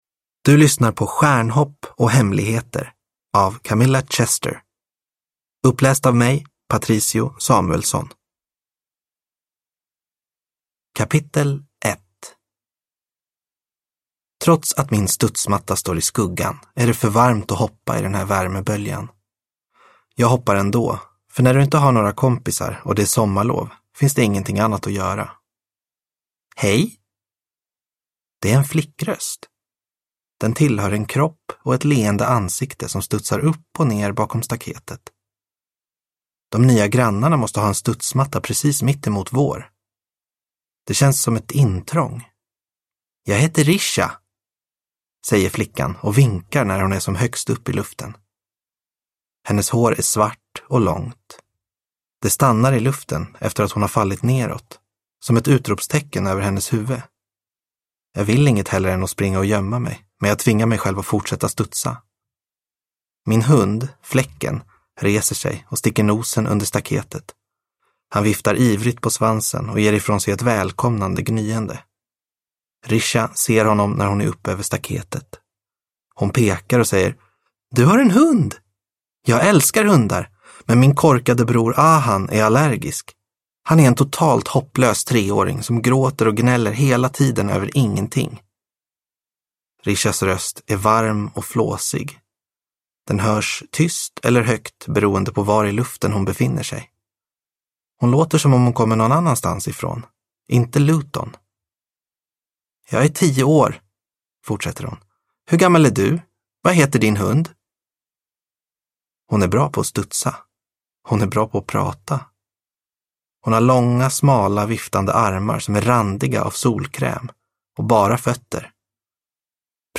Stjärnhopp och hemligheter – Ljudbok